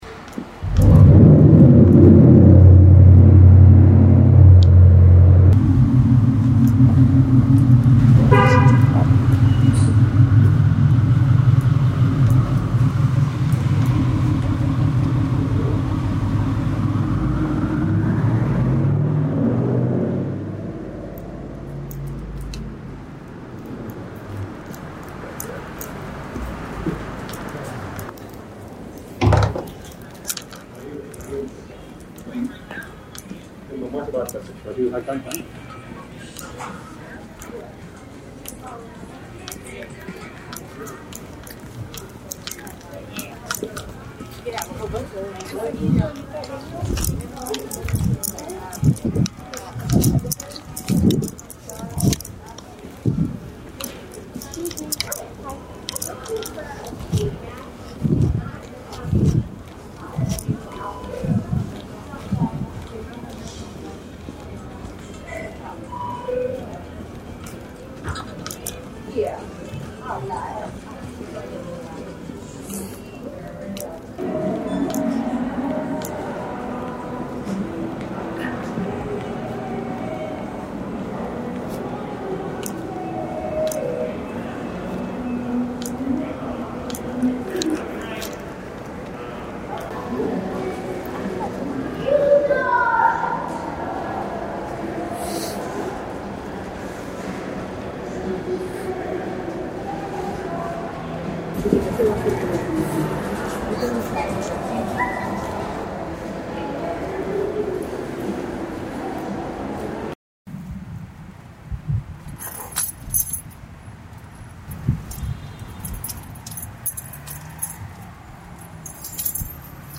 This soundscape is a collection of three different malls around the Hartford area as well as sounds from the new era of online shopping.
Sound mark signals are what is heard when these audios are capturing the different communities shopping at each mall.